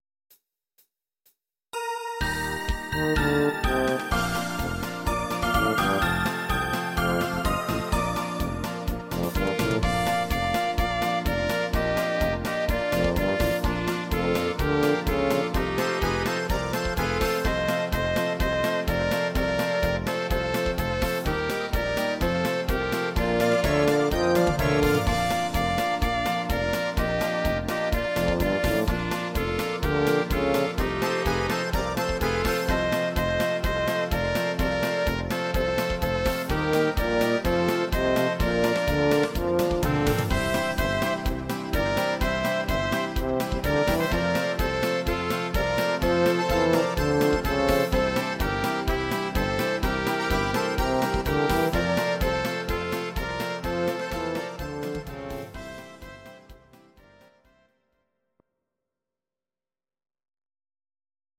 Audio Recordings based on Midi-files
German, Traditional/Folk, Volkst�mlich